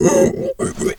pig_2_hog_single_04.wav